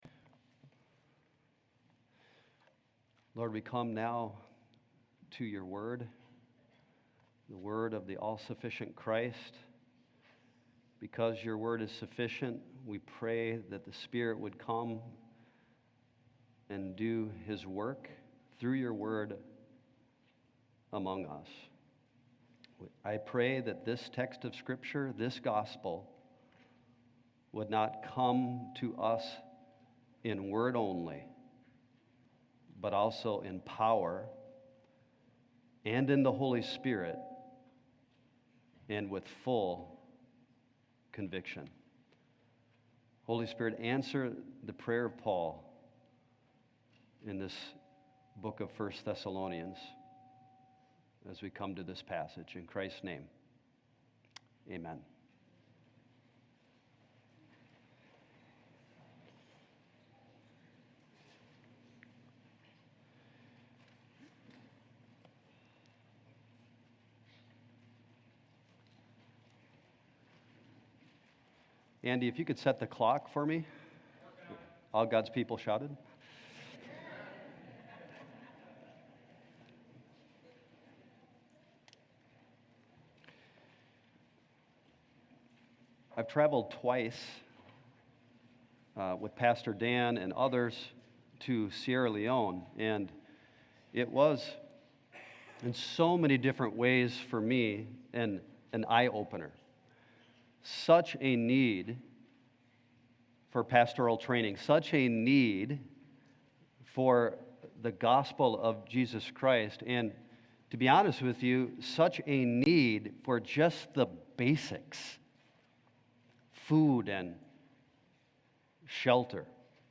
The All-Sufficient Christ | SermonAudio Broadcaster is Live View the Live Stream Share this sermon Disabled by adblocker Copy URL Copied!